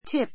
tip 2 A2 típ ティ プ 名詞 ❶ チップ, 心づけ Here's a tip for you.